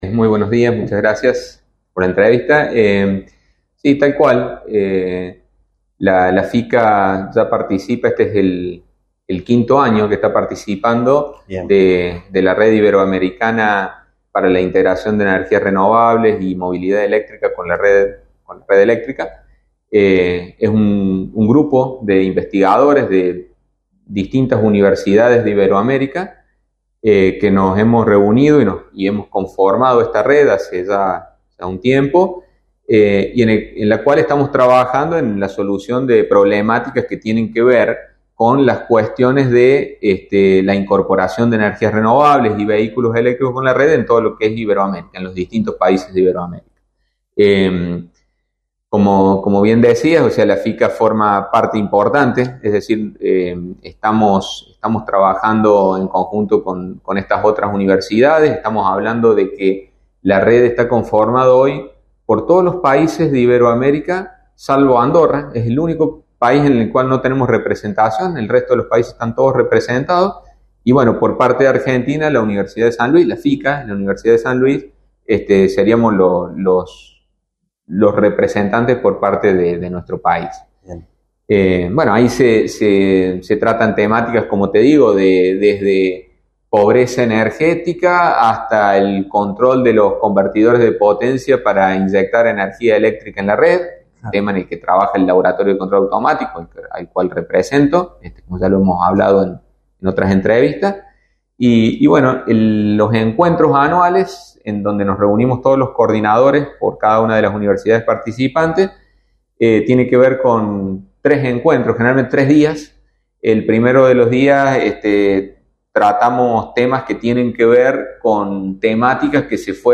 Desde Radio UNSL Villa Mercedes 97.5 FM dialogamos